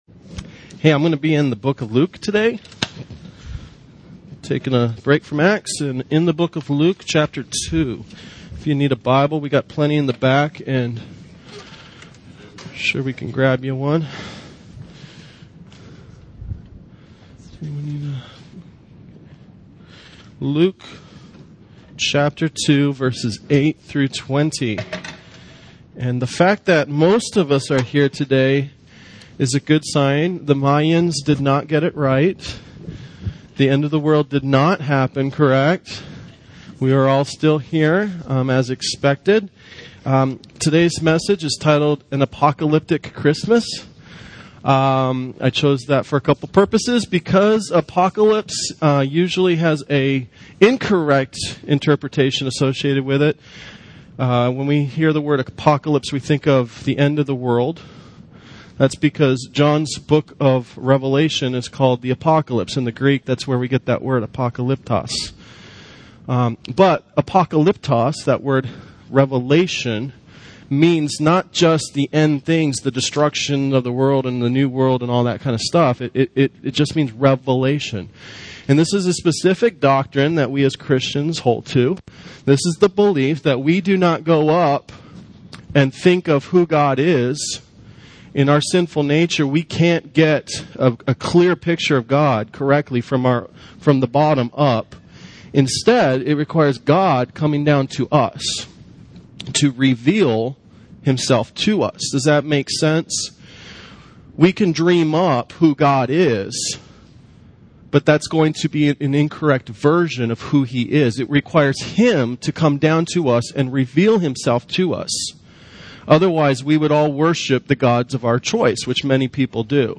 A Christmas Sermon